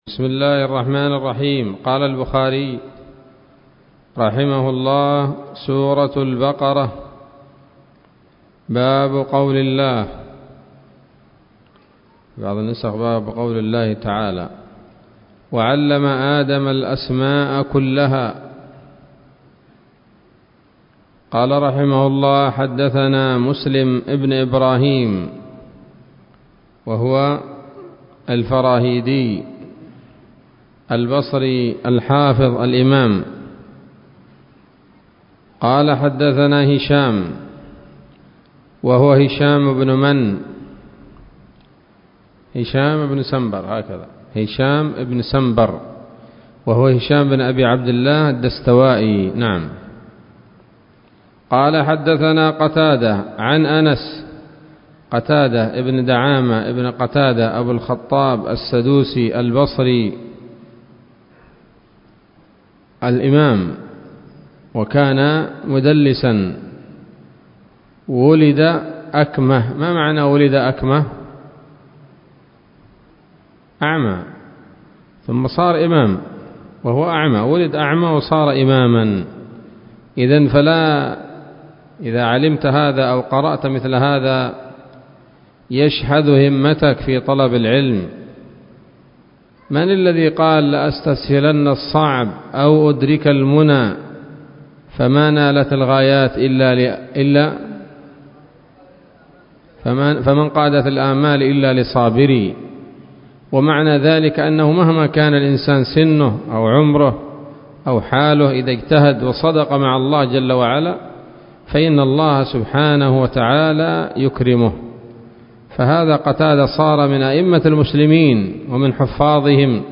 الدرس الثالث من كتاب التفسير من صحيح الإمام البخاري